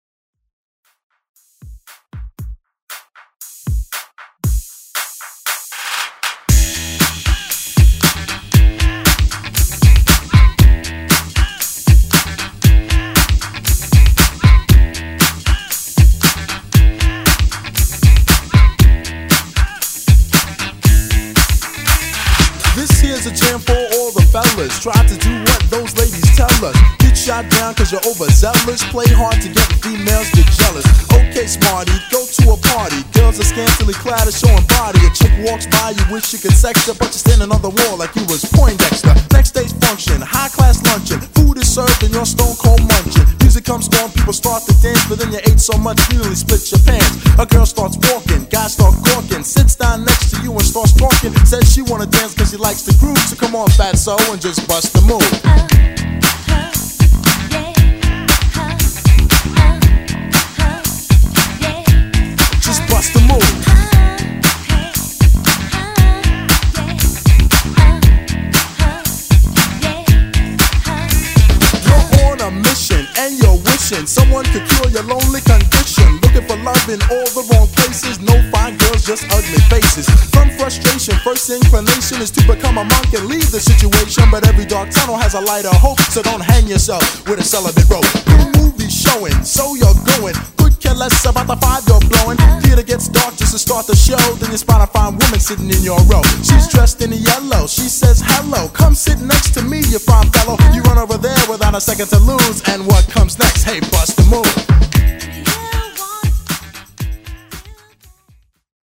Genre: OLD SCHOOL HIPHOP
Clean BPM: 117 Time